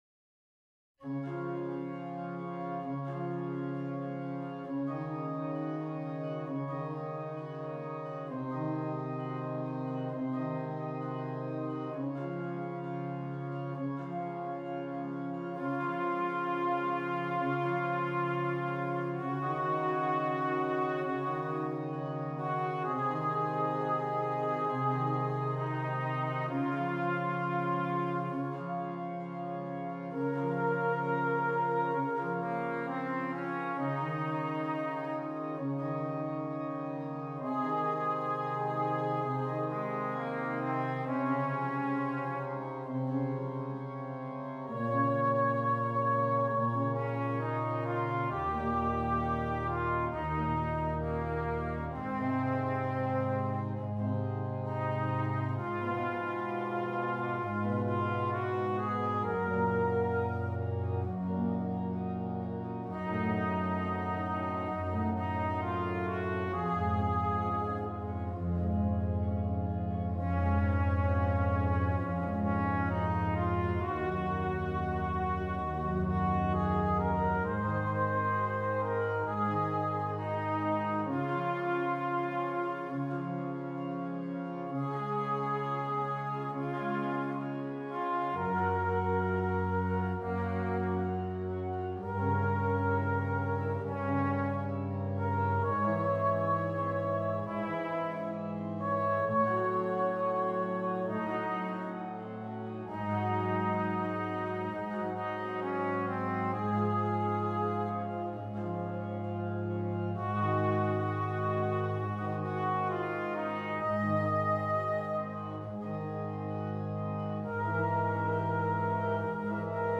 Trumpet and Keyboard